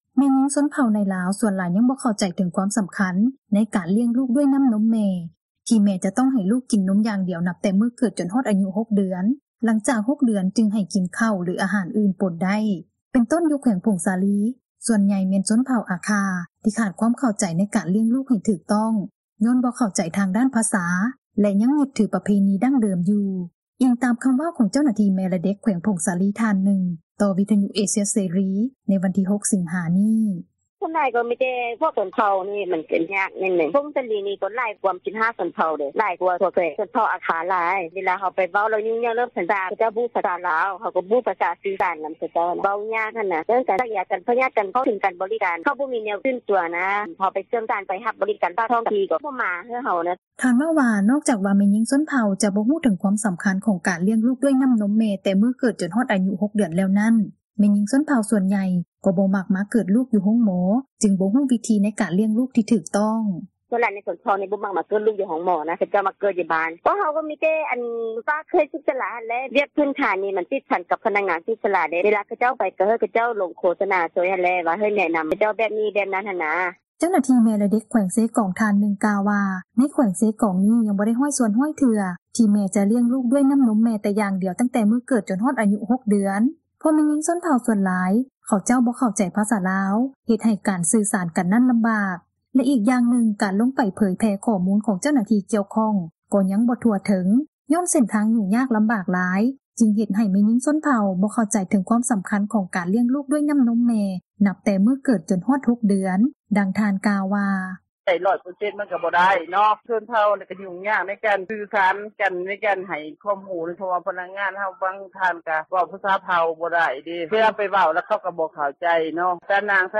ຊາວບ້ານຜູ້ນຶ່ງ ເວົ້າວ່າ ຕັ້ງແຕ່ສມັຍຕອນທີ່ຕົນເອງເກີດລູກ ກໍຕ້ອງໄດ້ເກີດລູກຢູ່ເຮືອນ ເພາະຢູ່ບ້ານນອກ ຣົດໂດຍສານກໍມີ ຕ້ອງໄດ້ເບິ່ງແຍງຕົນເອງ ແລະລ້ຽງລູກຕາມຮີດຄອງປະເພນີ ທີ່ພໍ່ແມ່ພາປະຕິບັດມາ